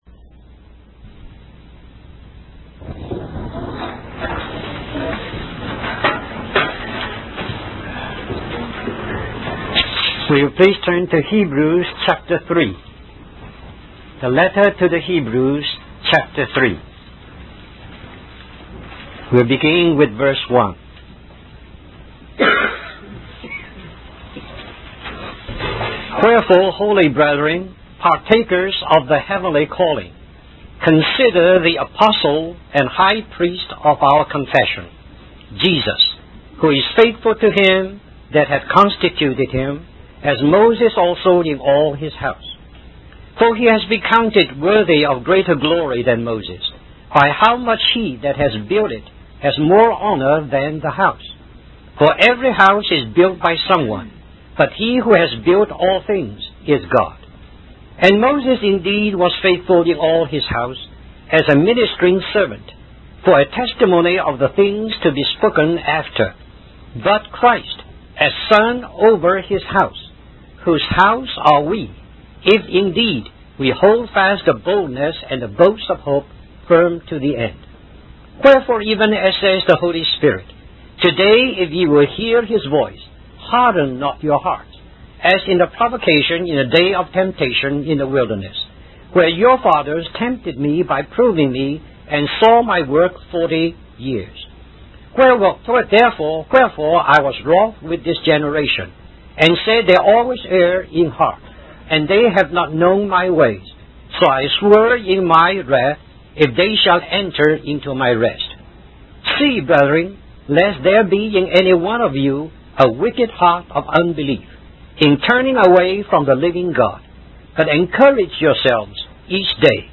In this sermon, the speaker emphasizes the importance of the theme of salvation and warns against hardening our hearts towards God. The first exhortation focuses on the concept of 'rest' and how it is only possible when the work of salvation is finished. The second exhortation highlights the heavenly calling that believers have received and the responsibility that comes with it.